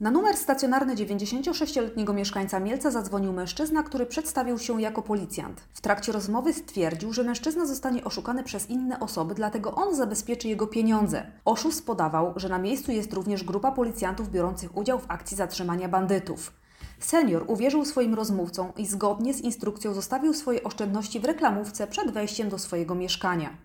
Mówi podkom.